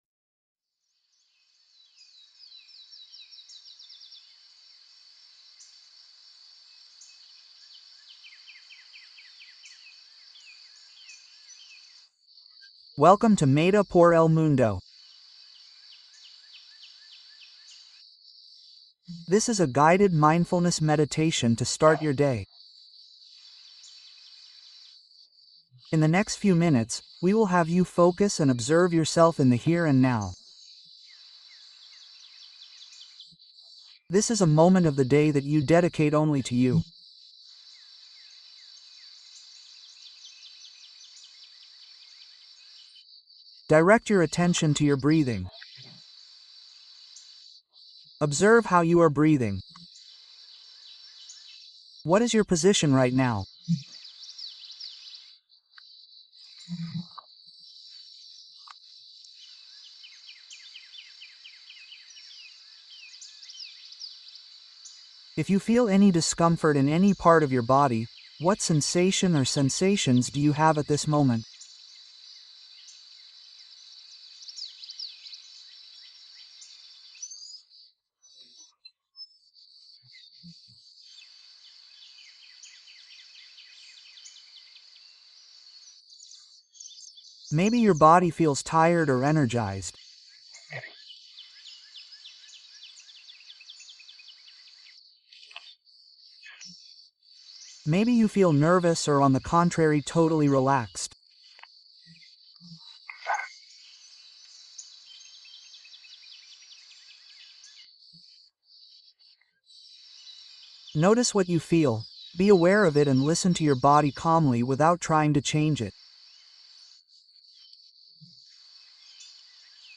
Explora Tu Intuición Interna: Meditación de Activación del Tercer Ojo